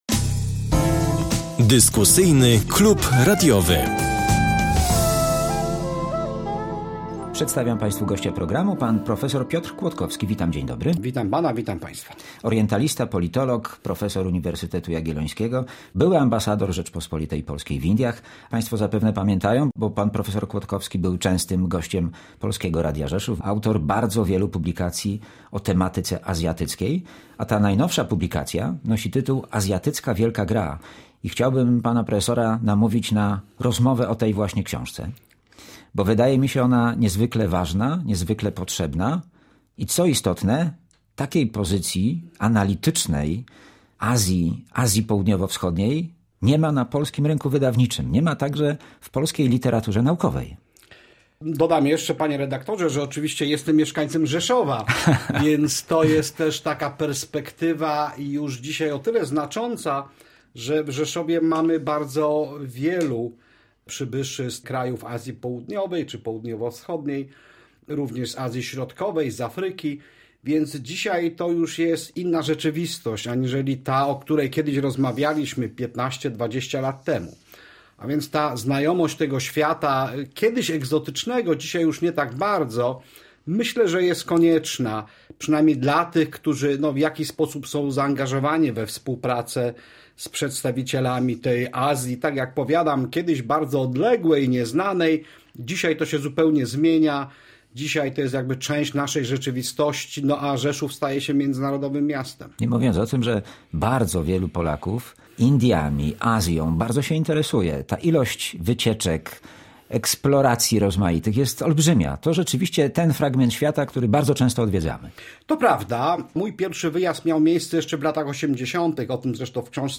W Dyskusyjnym Klubie Radiowym rozważania na temat zmieniających na świecie wpływów polityczno-gospodarczo-społecznych.